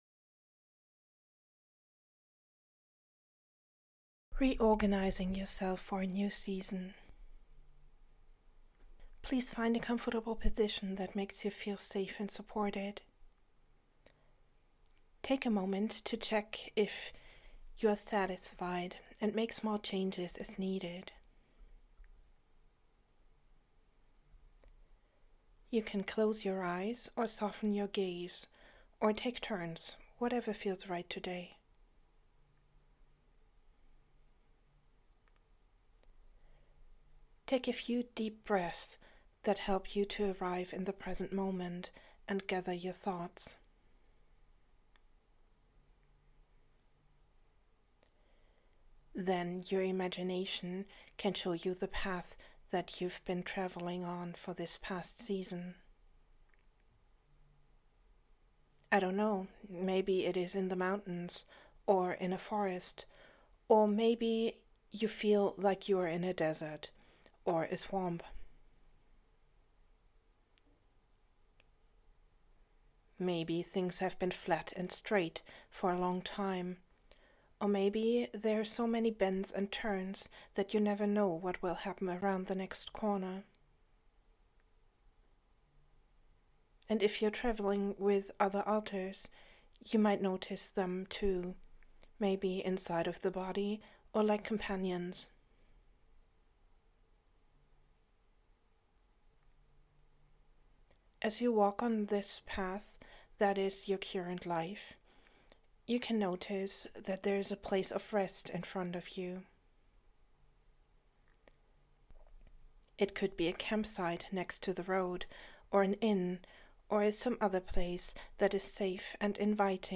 this guided imagery is for seasons of change and new beginnings. it helps us to look back, sort through our things and receive a gift